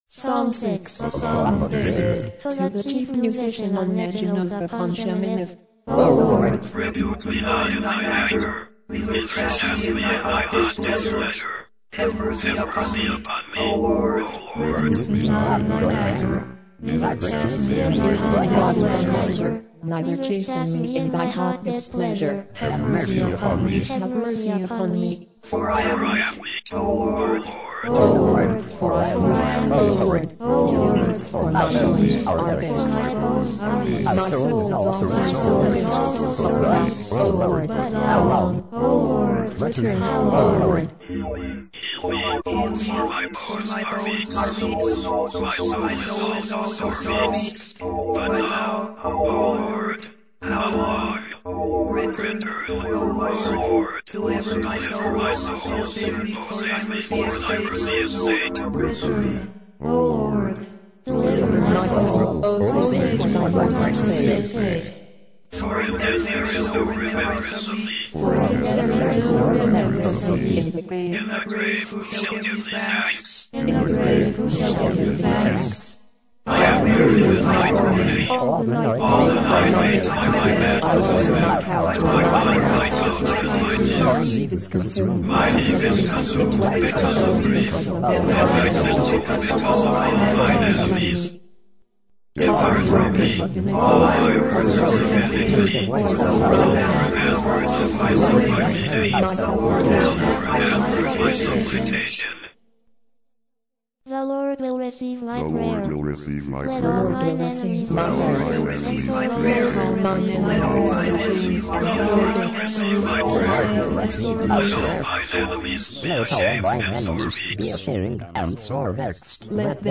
религиозная музыка